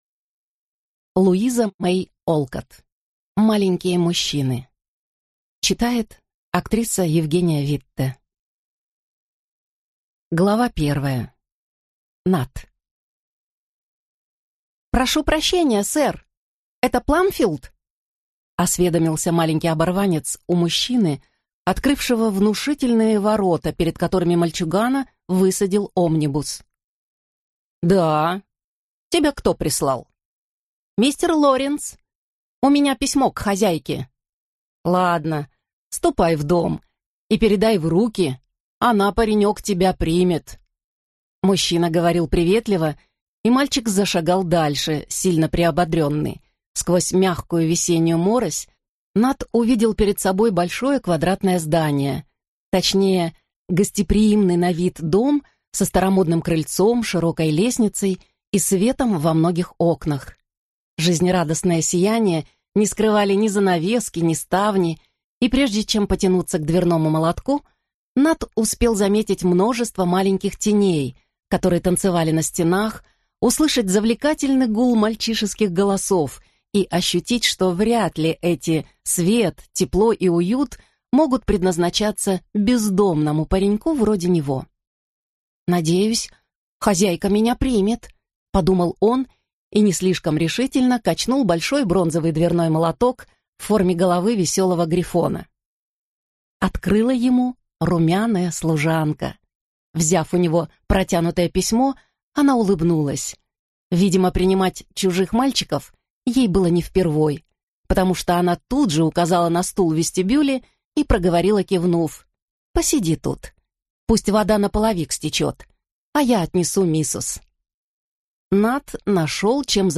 Аудиокнига Маленькие мужчины | Библиотека аудиокниг